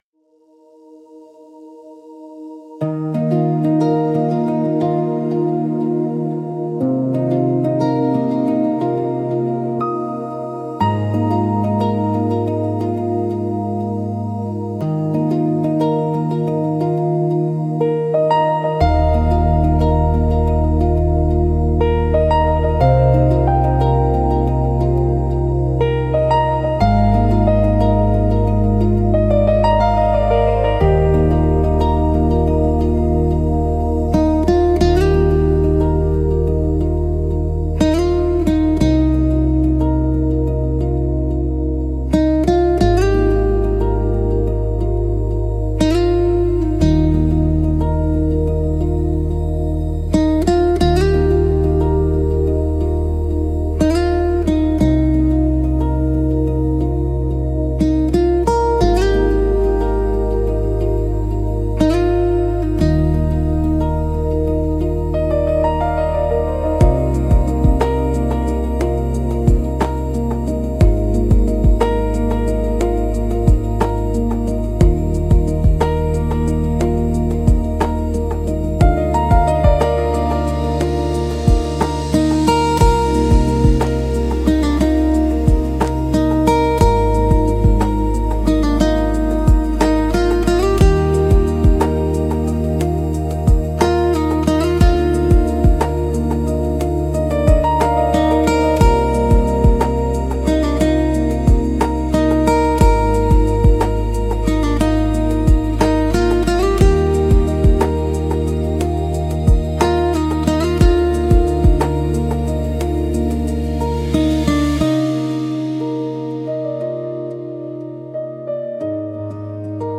「爽やかな」カテゴリーの関連記事